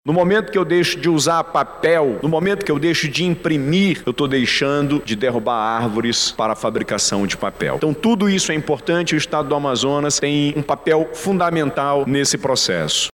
Além dos custos financeiros e de tempo, o Estado, também, contribui com a preservação do Meio Ambiente, conforme destaca o governador do Amazonas, Wilson Lima.